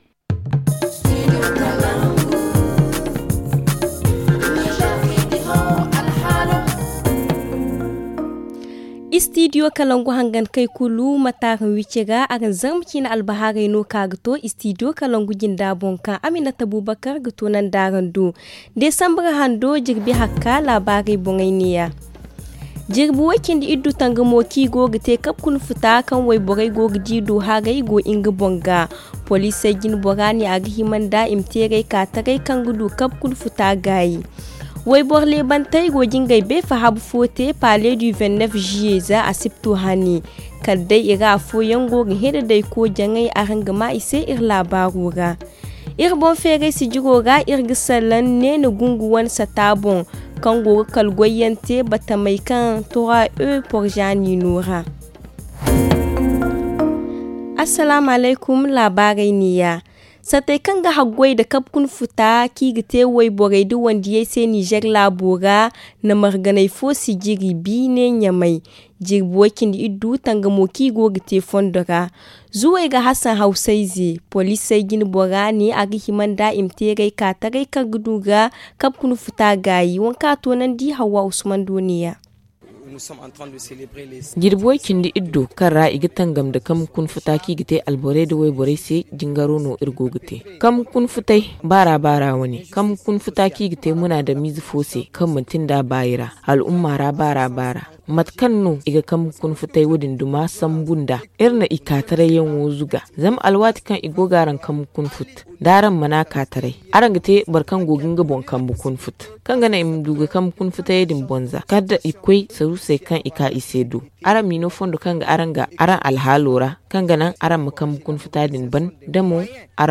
Le journal du 8 novembre 2022 - Studio Kalangou - Au rythme du Niger